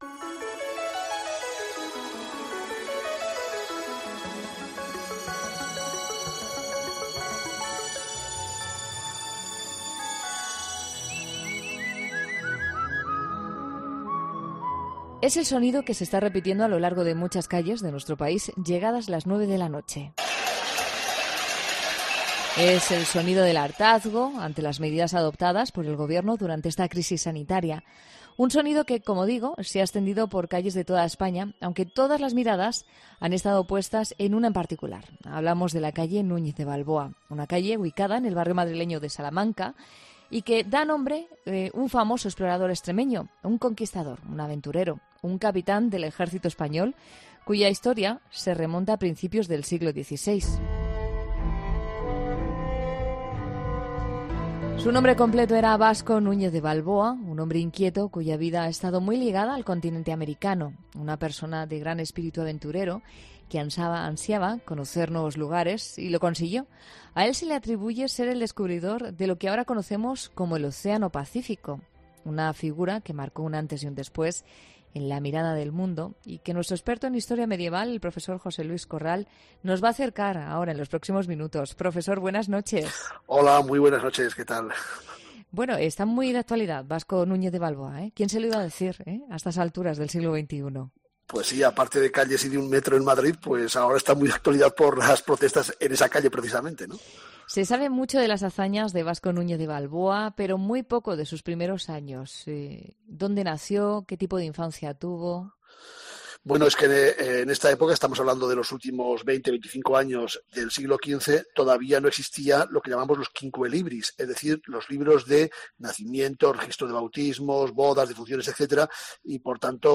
El profesor y catedrático en historia medieval, José Luis Corral, nos acerca la fascinante vida de esta figura